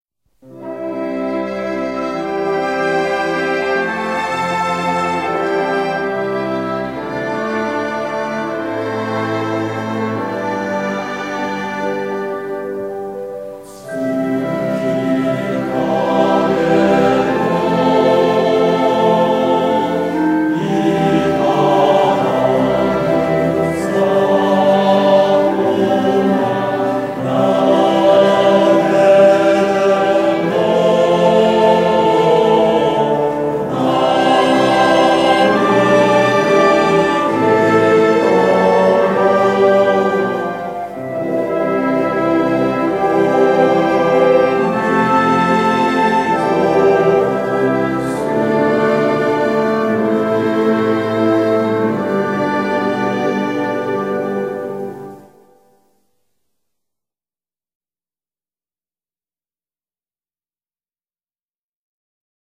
古旋律